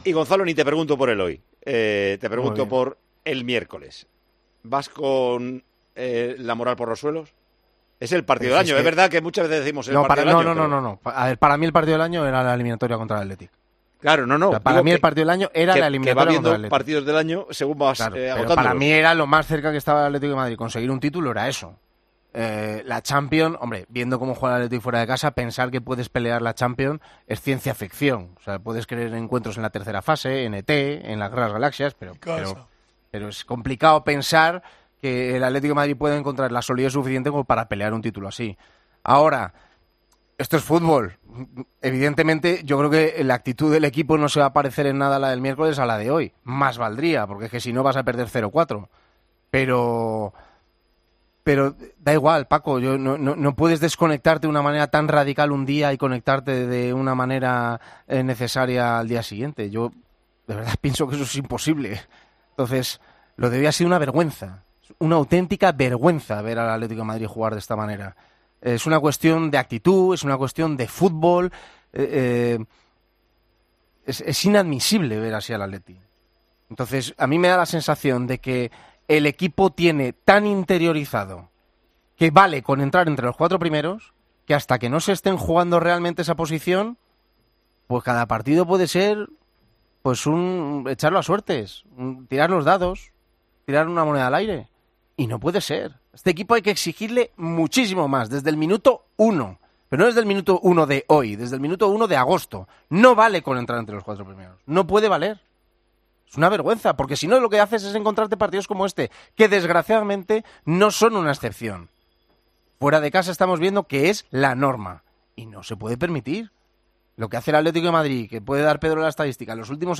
ESCUCHA LA 'RAJADA' DE GONZALO MIRÓ CONTRA EL ATLÉTICO DE MADRID TRAS LA DERROTA EN CÁDIZ